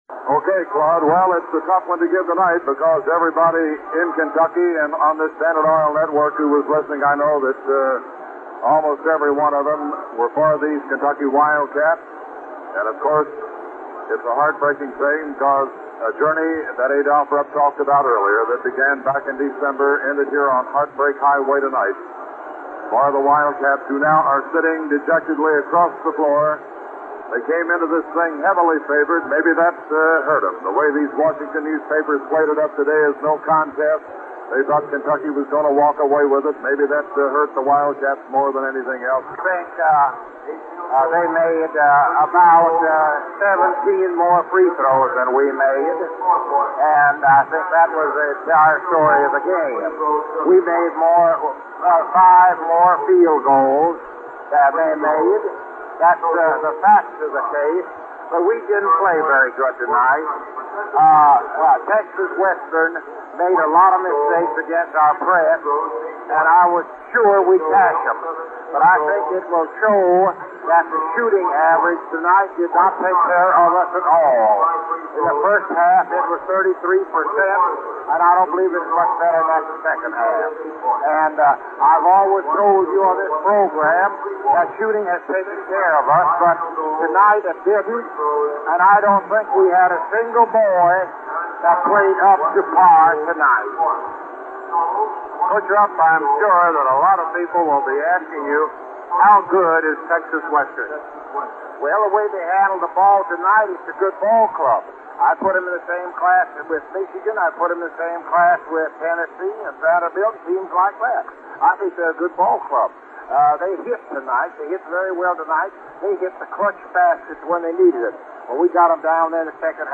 Audio Clip after 1966 Texas Western Game